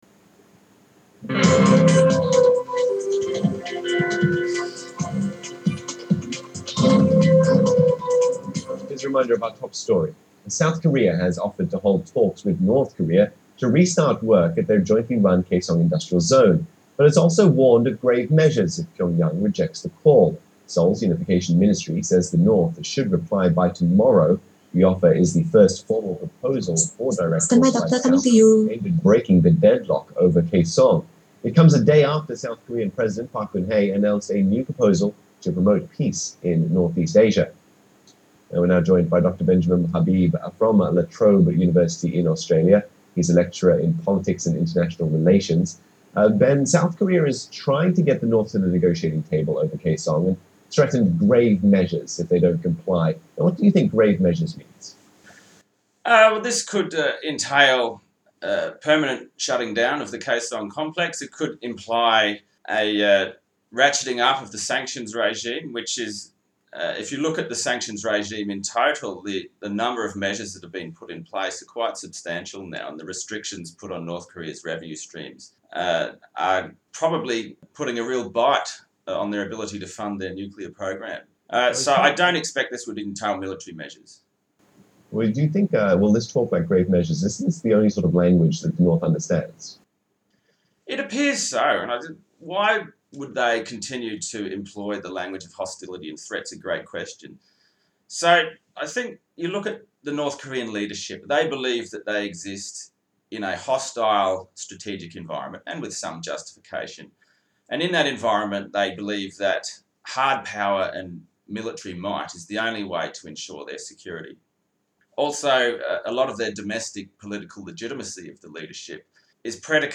TVThis afternoon I was interviewed for the Asia Connect program on Singapore’s Channel News Asia.